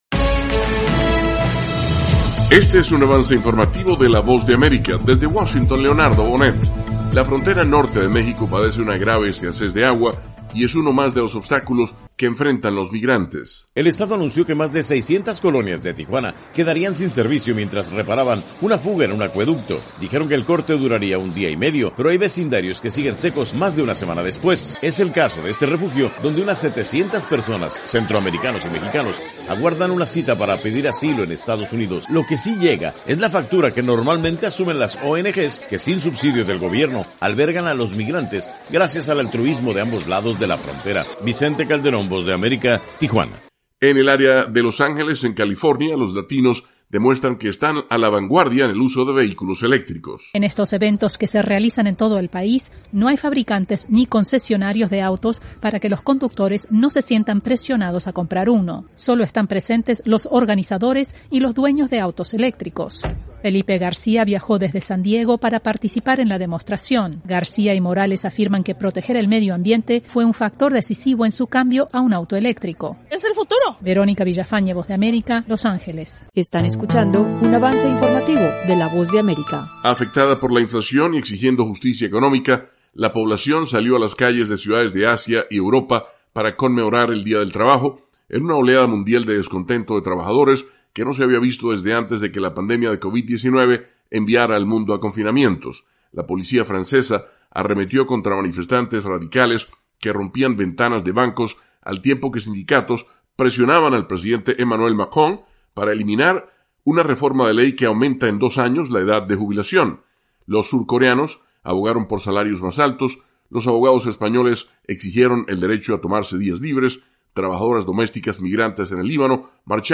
Avance Informativo 1:00 PM
El siguiente es un avance informativo presentado por la Voz de América, desde Washington